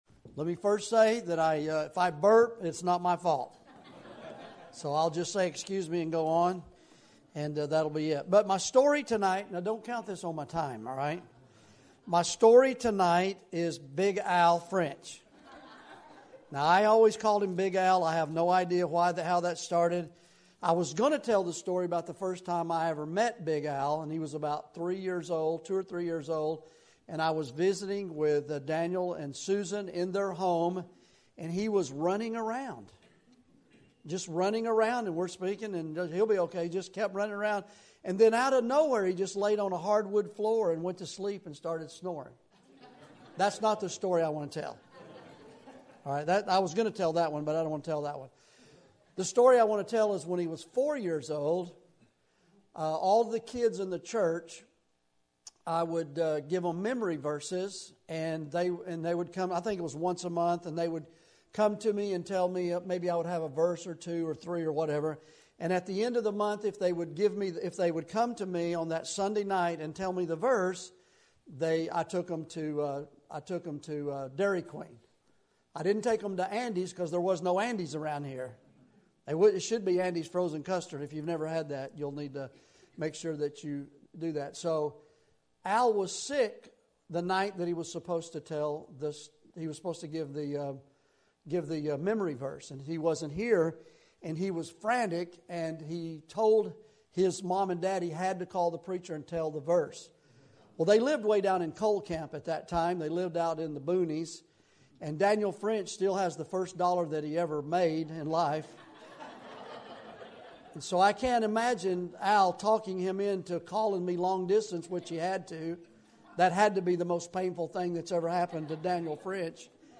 Sermon Topic: Winter Revival Sermon Type: Special Sermon Audio: Sermon download: Download (23.93 MB) Sermon Tags: Matthew Revival Beatitudes Disciple